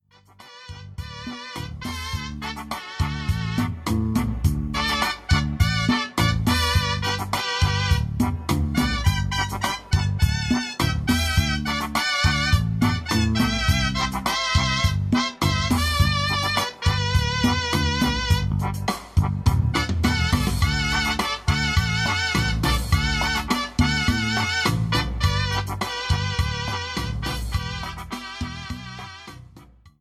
105-Banda-Balada.mp3